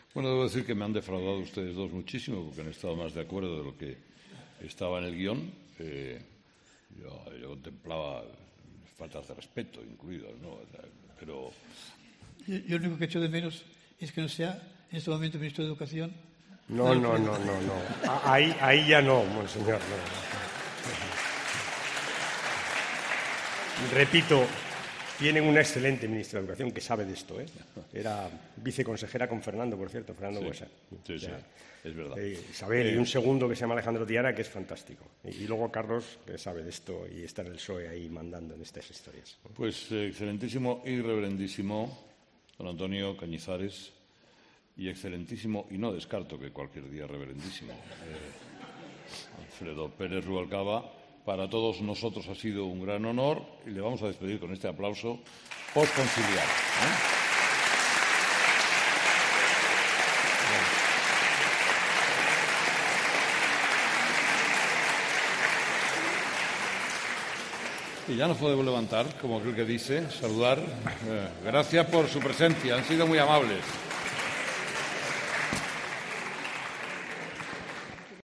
En un debate con Rubalcaba sobre el papel de la Iglesia en la educación, el Cardenal aseguró que el PSOE permitió la 'libertad de enseñanza en España'